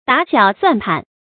打小算盤 注音： ㄉㄚˇ ㄒㄧㄠˇ ㄙㄨㄢˋ ㄆㄢˊ 讀音讀法： 意思解釋： 在小處精打細算，斤斤計較。